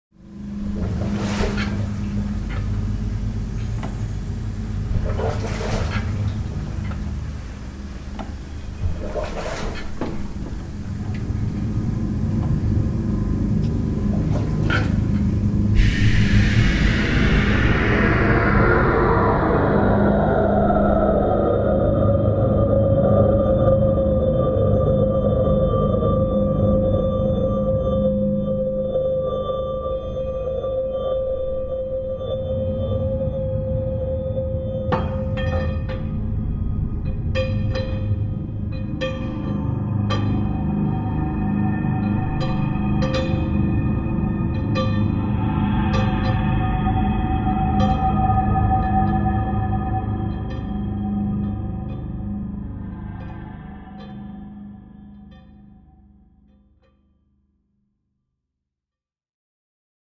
Site Specific Music for Eight Channel Tape and Steam Engines